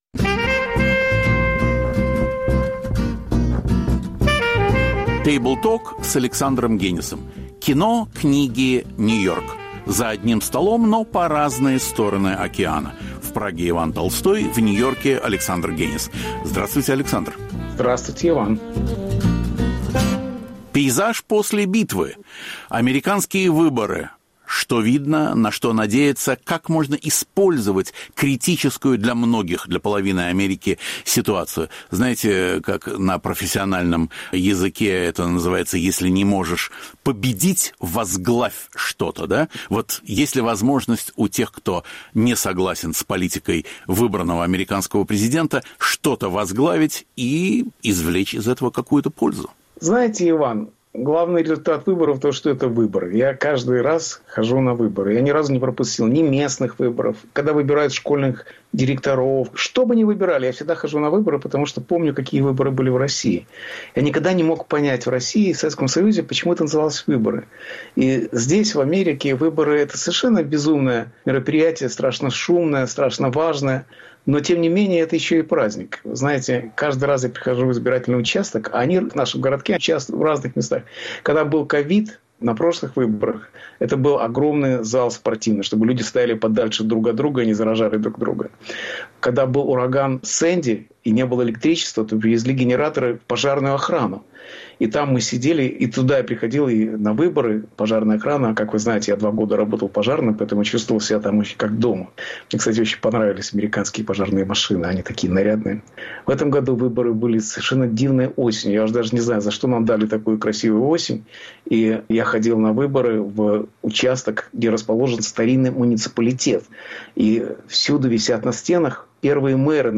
С ним беседует Игорь Померанцев